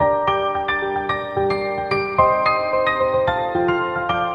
woman sexy shhh
描述：Woman whispering shhhh 4 separate times. Long slow shhhh.
标签： shhh sexy whispering naughty hot steam shush Woman coffee
声道立体声